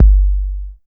20 808 KICK.wav